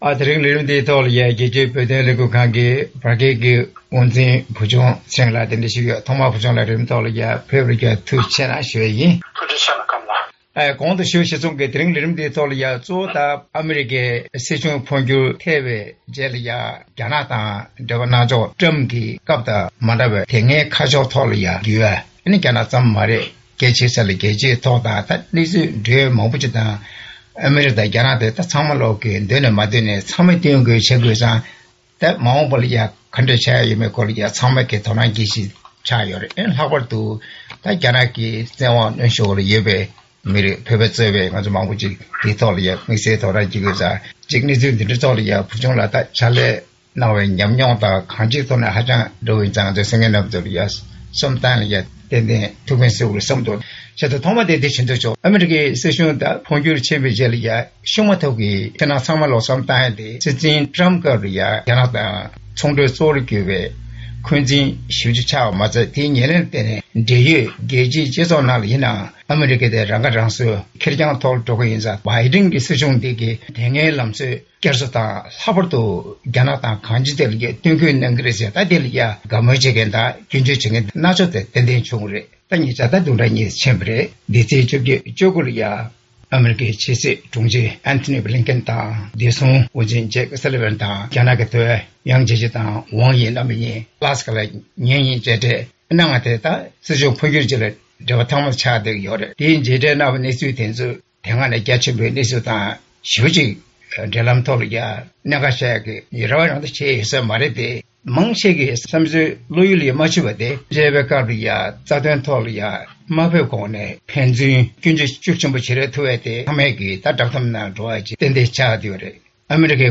གླེང་མོལ་ཞུས་པ་གསན་རོགས་གནང་།